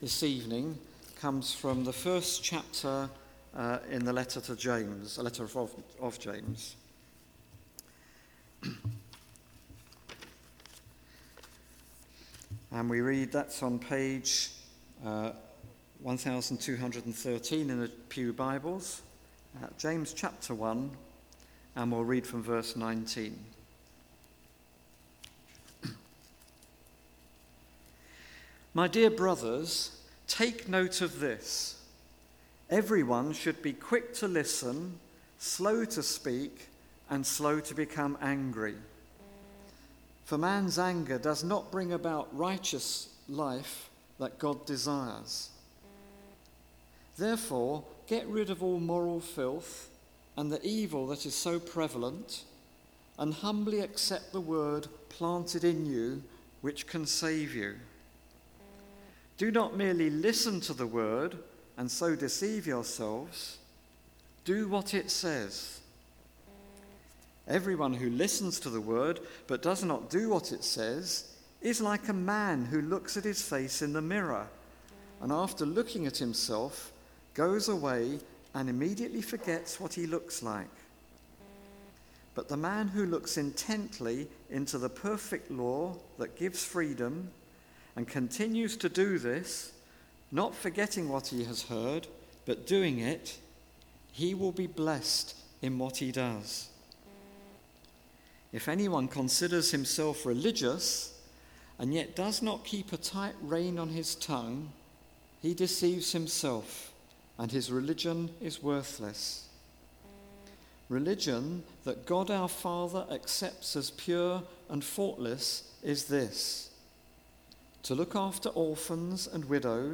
Guest Speaker , Evening Service